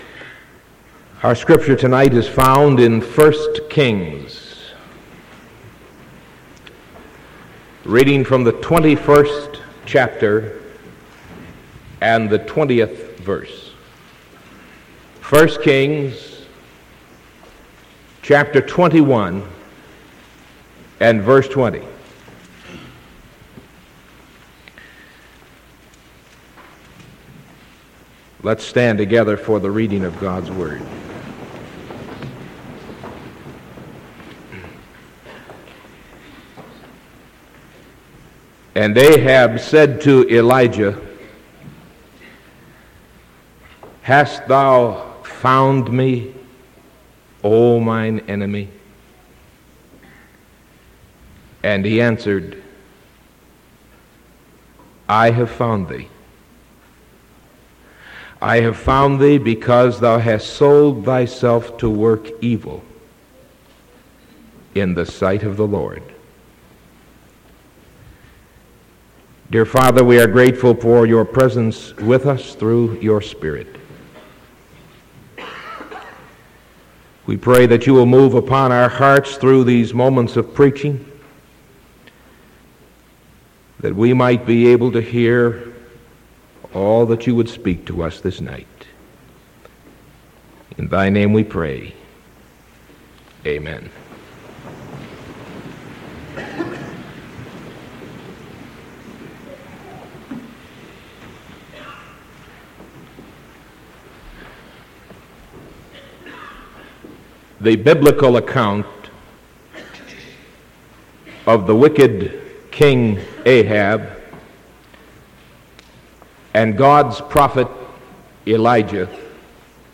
Sermon from November 16th 1975 PM